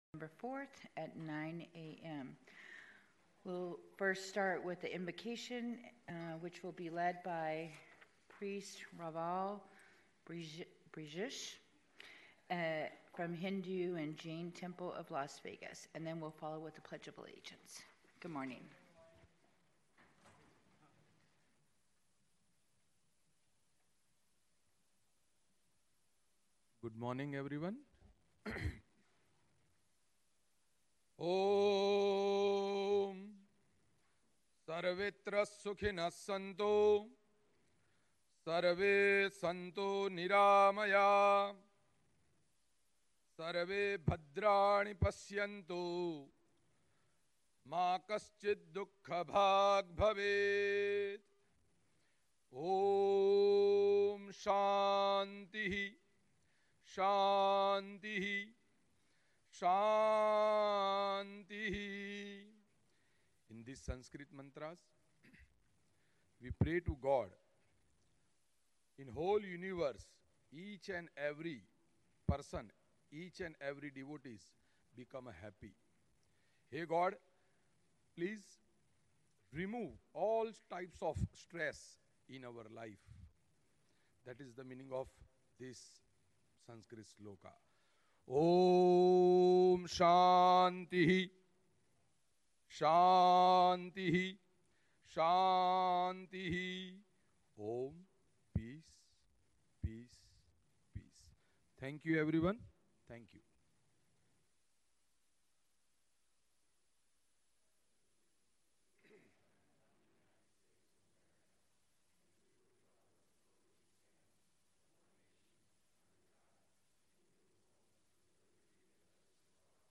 County of Clark: Recent Audio Podcast Author: County of Clark Language: en-us Genres: Government Contact email: Get it Feed URL: Get it iTunes ID: Get it Get all podcast data Listen Now... Las Vegas Valley Water District Board Meeting